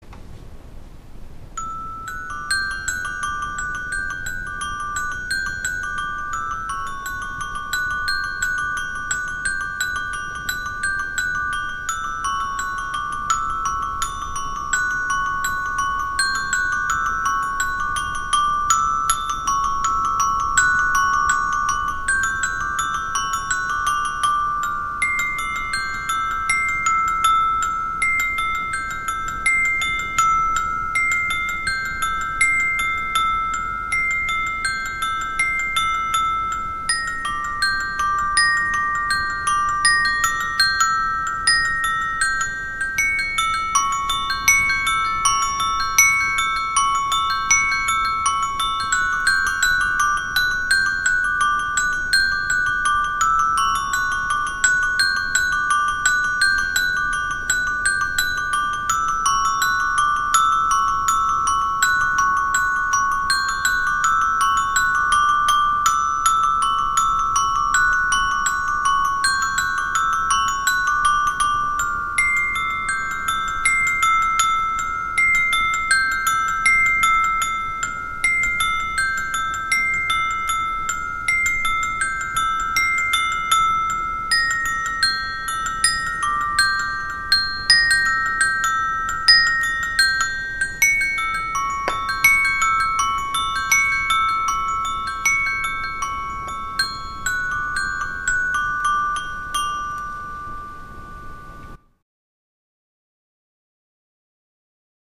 TuneForAFoundHarmonium.mp3